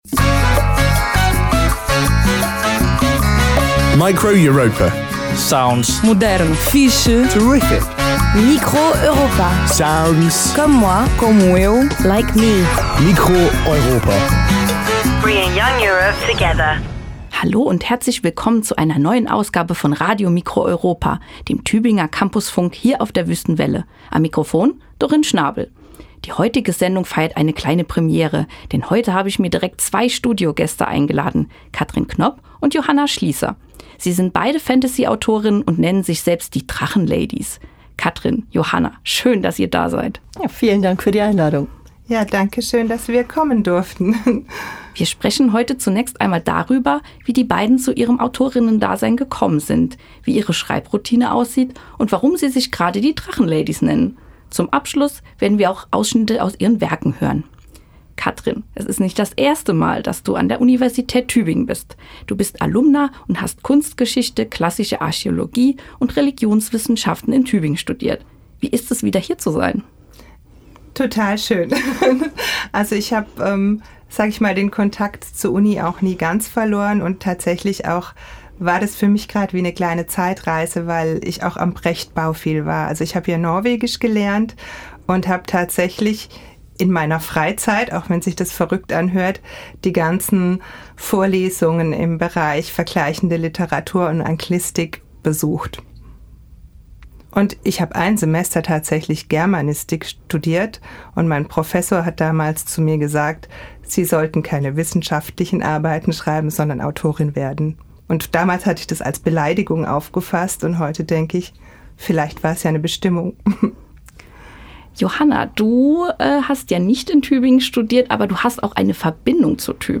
Literaturgespräch
Sie sind beide Fantasy-Autorinnen und nennen sich selbst „die Drachenladys“.
Zum Abschluss sind Ausschnitte aus ihren Werken zu hören.
Form: Live-Aufzeichnung, geschnitten